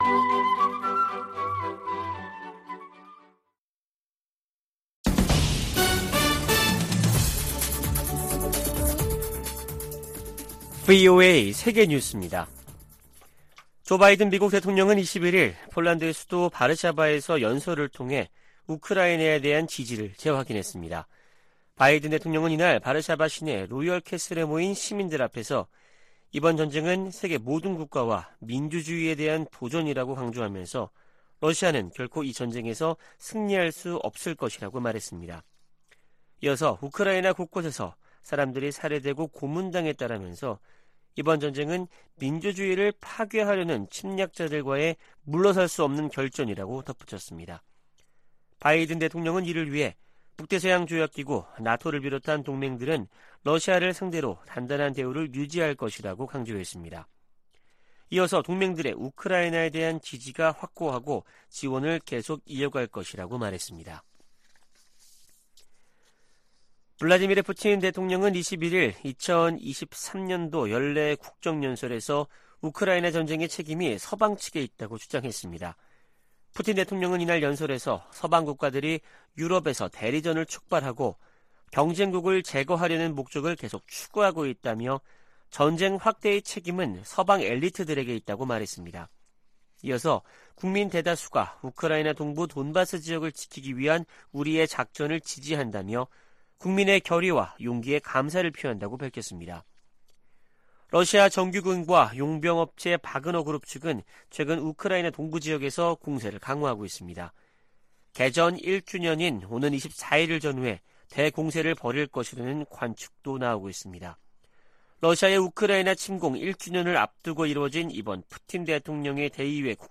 VOA 한국어 아침 뉴스 프로그램 '워싱턴 뉴스 광장' 2023년 2월 22일 방송입니다. 유엔 안전보장이사회가 북한의 대륙간탄도미사일(ICBM) 발사에 대응한 공개회의를 개최한 가운데 미국은 의장성명을 다시 추진하겠다고 밝혔습니다.